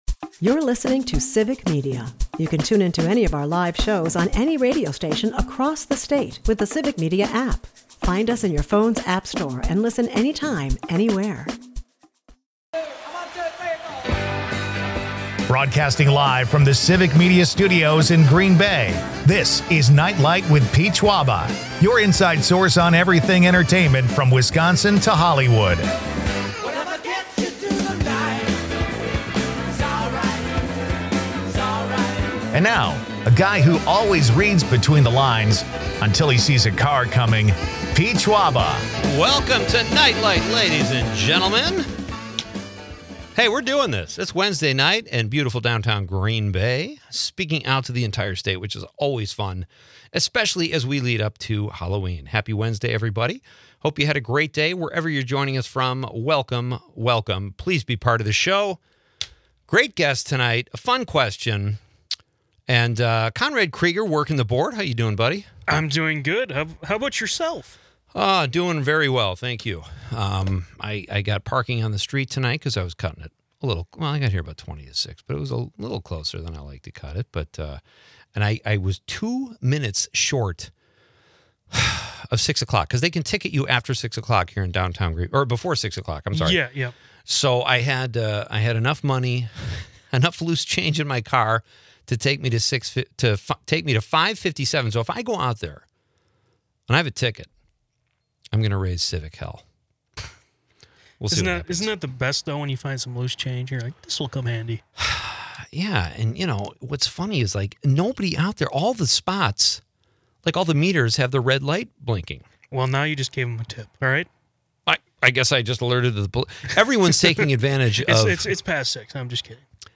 Amidst light-hearted banter, the episode underscores profound narratives of loss and hope.